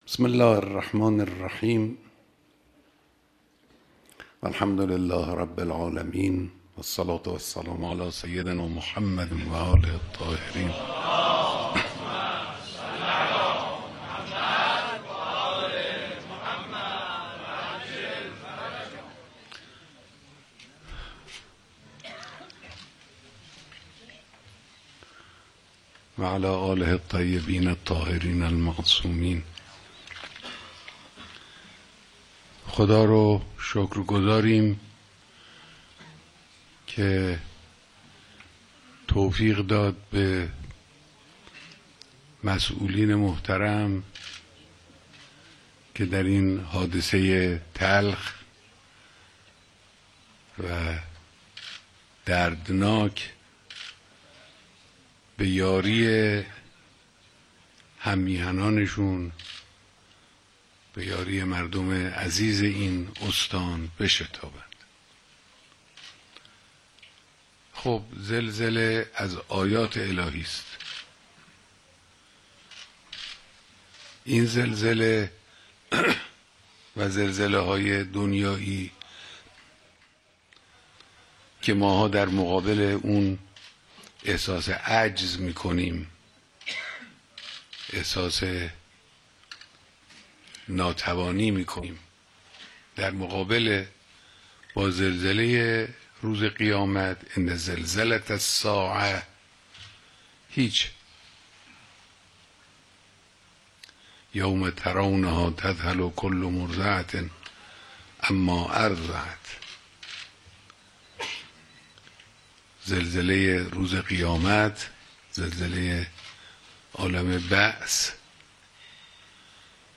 بیانات در جلسه رسیدگی به مشکلات زلزله‌زدگان